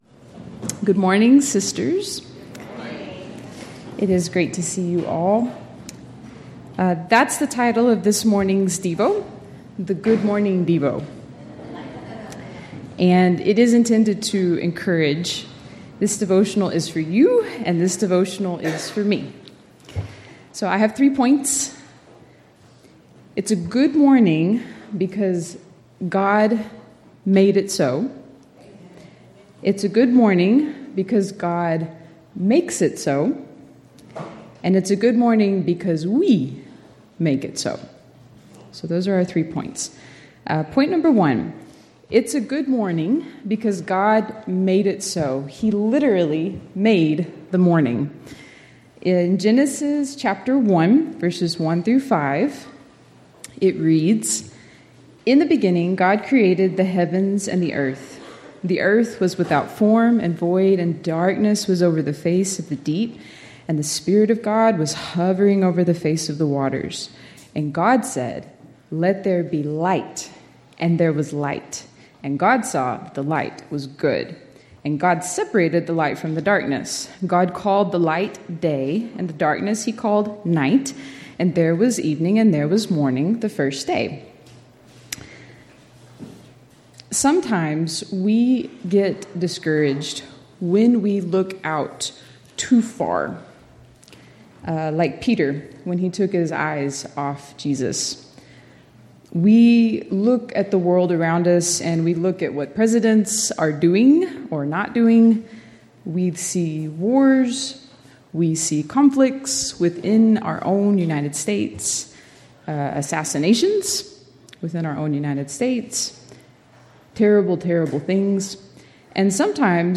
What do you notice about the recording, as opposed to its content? Event: 13th Annual Texas Ladies in Christ Retreat Theme/Title: Studies in 1 Corinthians